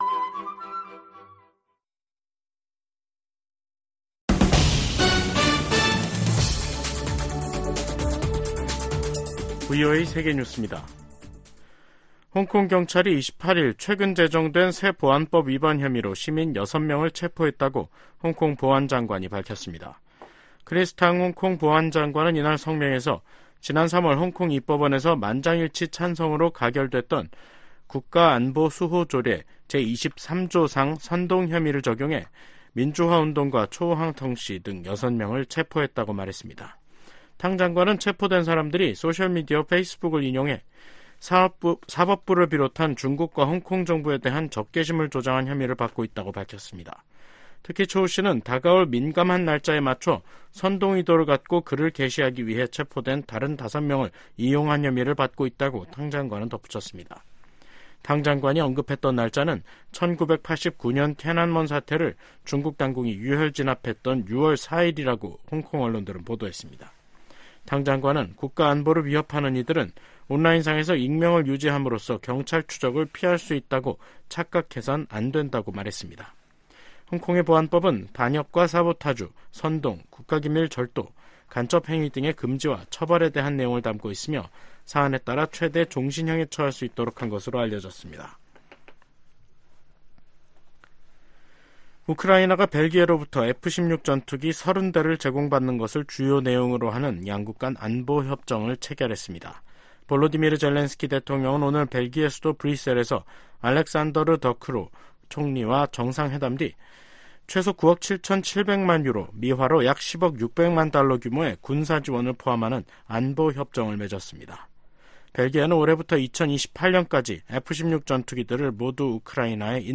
VOA 한국어 간판 뉴스 프로그램 '뉴스 투데이', 2024년 5월 28일 3부 방송입니다. 북한이 27일 밤 ‘군사 정찰위성’을 발사했지만 실패했습니다.